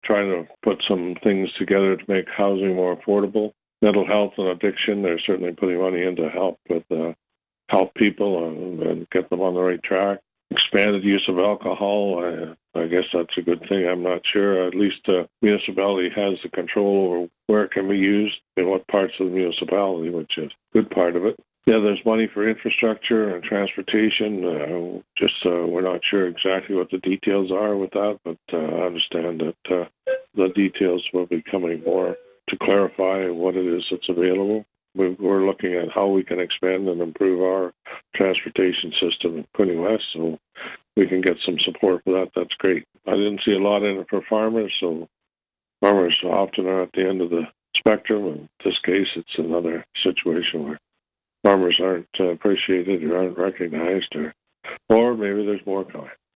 Quinte West Mayor Jim Harrison